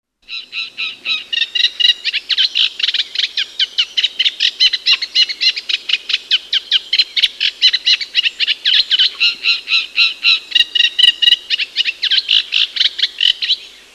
Birds Sounds Archive